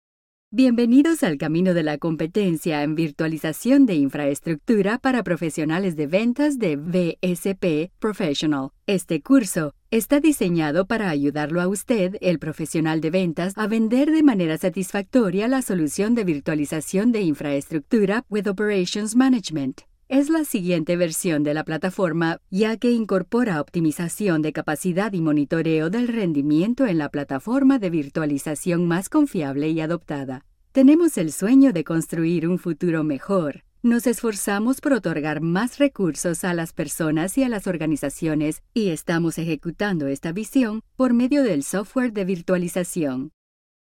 Neutral Latin American Spanish female voice.
Sprechprobe: Industrie (Muttersprache):